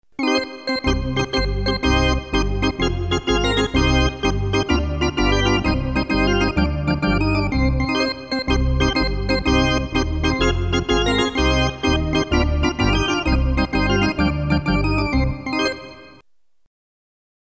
Category: Sports   Right: Personal
Tags: Princeton Hockey Baker Rink